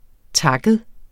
Udtale [ ˈtɑgəð ]